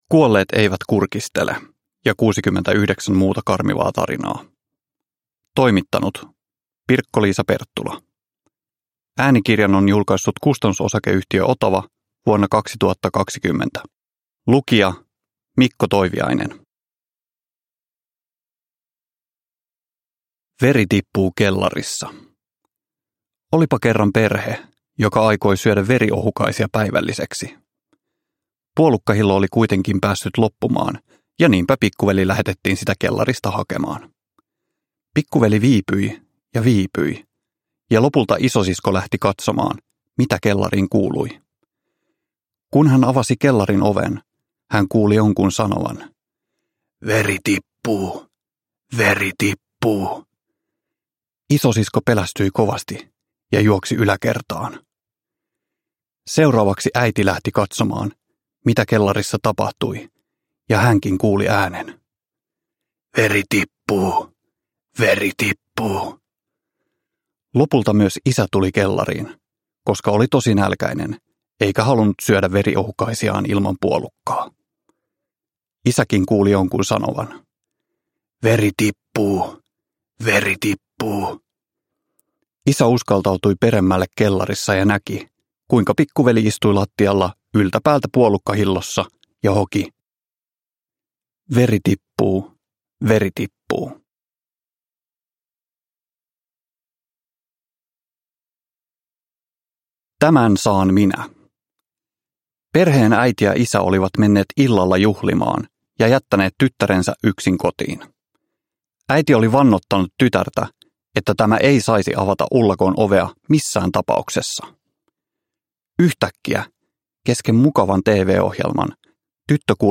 Kuolleet eivät kurkistele ja 69 muuta karmivaa tarinaa – Ljudbok – Laddas ner